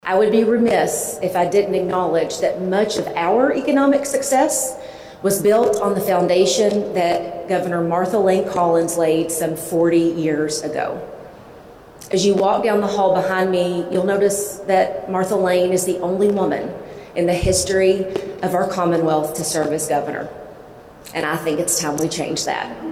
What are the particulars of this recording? During a Monday morning press conference at the Kentucky History Center’s “Hall of Governors” in Frankfort, the 43-year-old from Mercer County announced her 2027 candidacy — a full year before the gubernatorial primary — while sending a clear message to the Commonwealth.